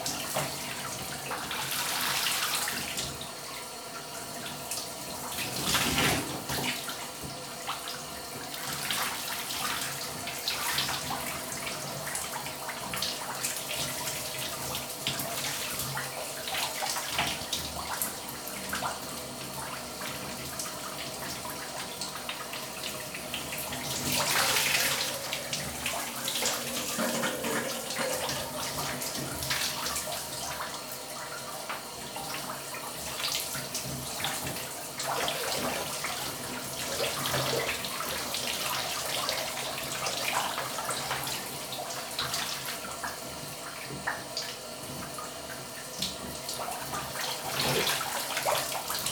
household
Shower Noise